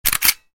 Звуки скриншота
Звук скриншота на клавиатуре для видеомонтажа